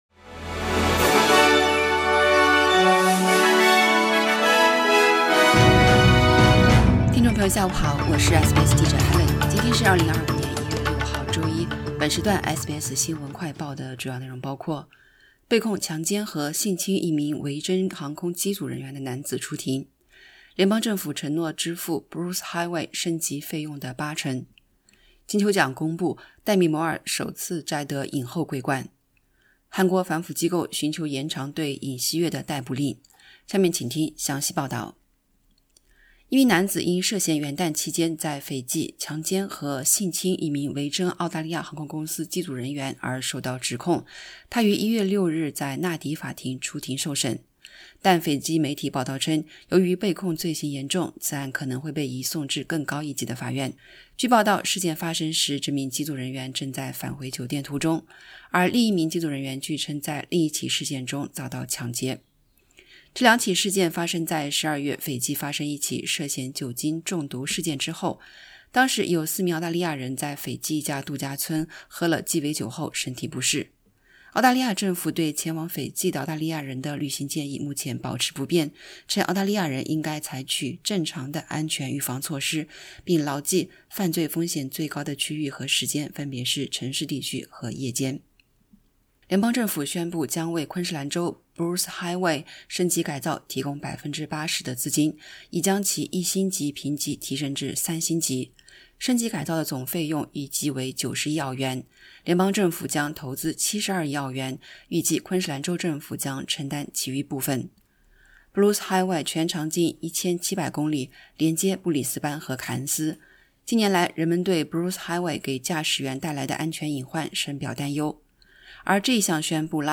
【SBS新闻快报】被控强奸维珍航空机组人员的男子出庭